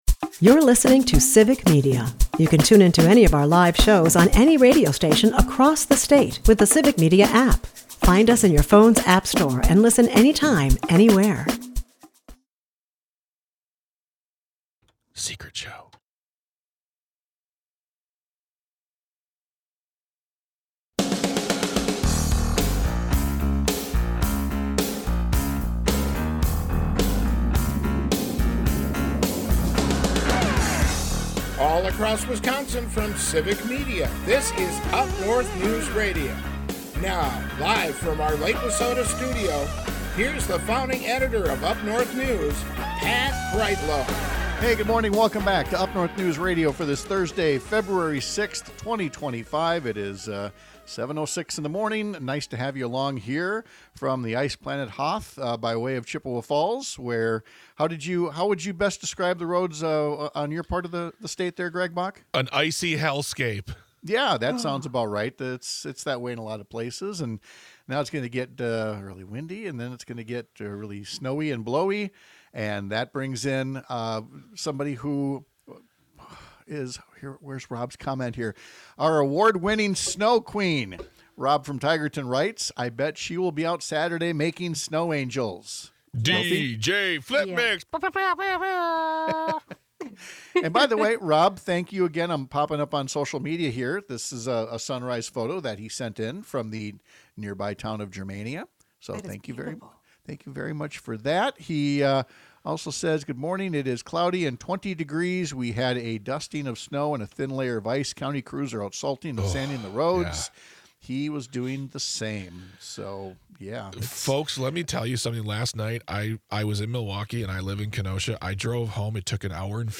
Broadcasts live 6 - 8 a.m. across the state!
Even right-wing politicians in Madison and Washington launch new attacks against the transgender community, the Wisconsin Legislature’s LGBTQ caucus is growing and attracting more allies. That’s part of our conversation with two new legislators: Sen. Kristin Dassler-Alfheim and Rep. Christian Phelps.